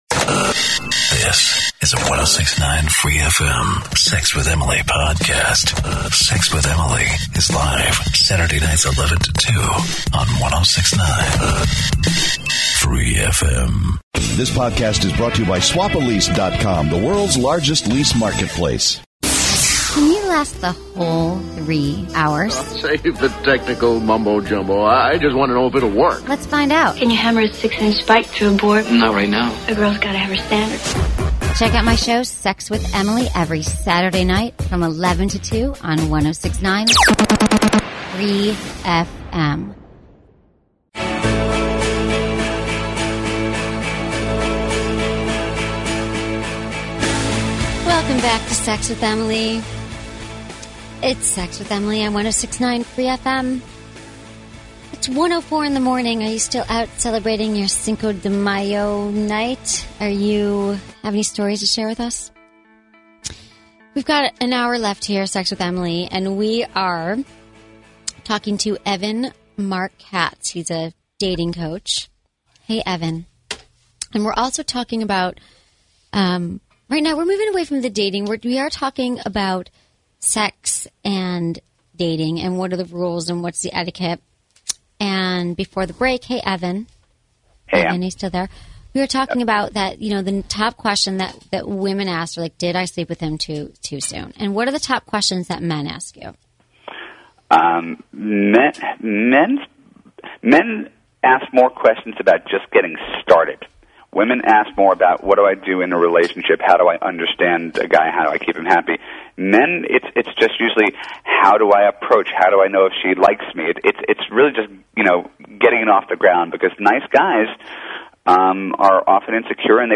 Interview 1
Tags: Media Radio Program Sex with Emily 106.9 Free FM Dating Advice